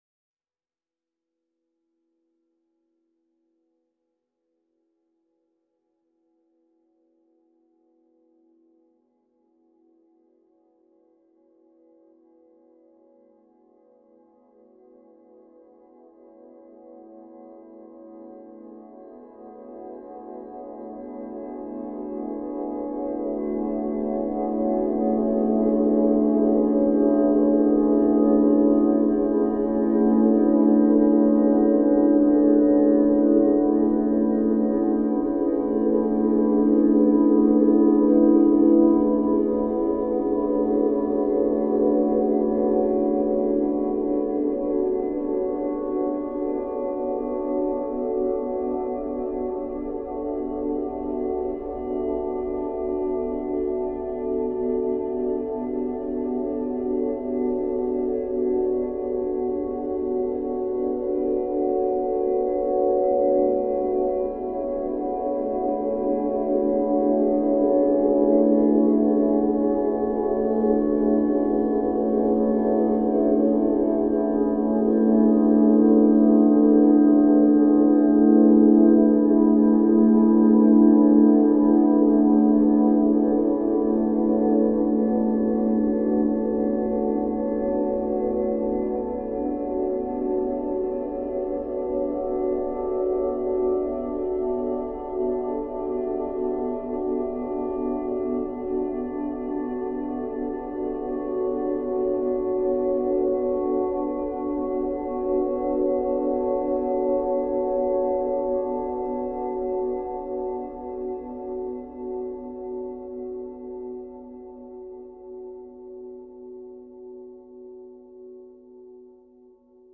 A collection of ambient tracks.